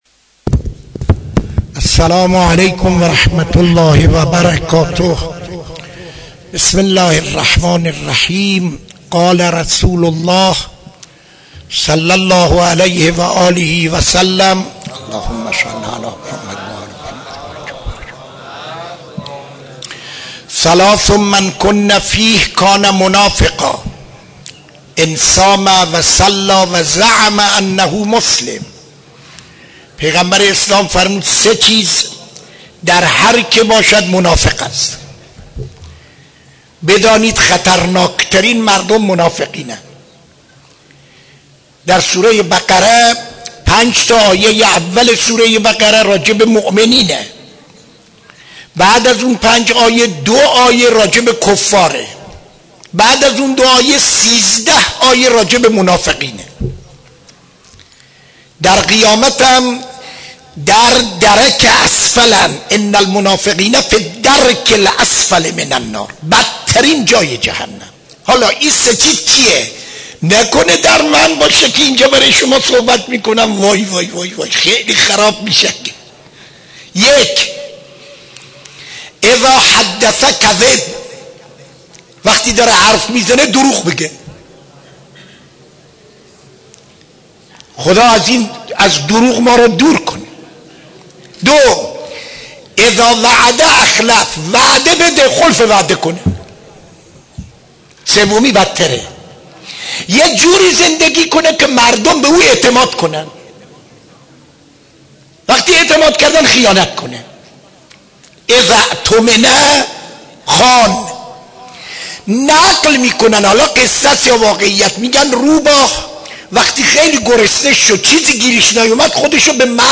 سخنرانی در مسجد دانشگاه (یکشنبه 10-08-94)